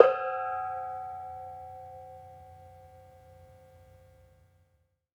Bonang-C4-f.wav